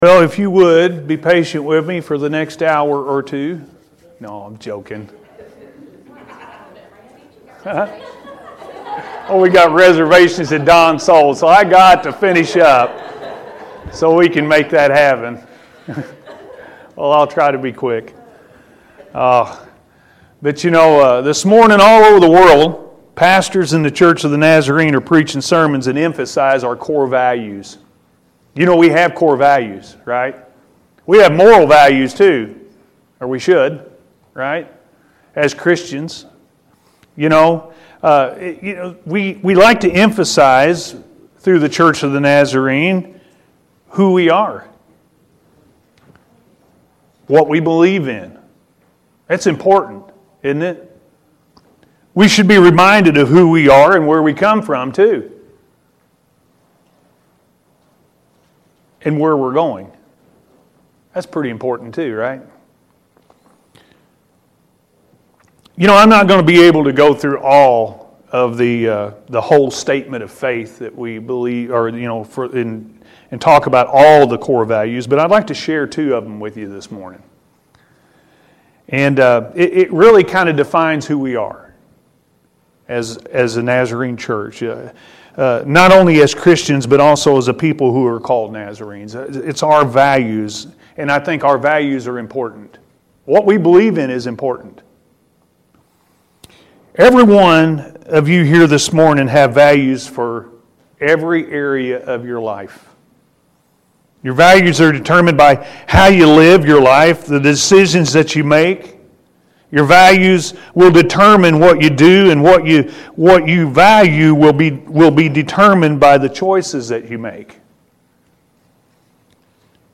Our Core Values-A.M. Service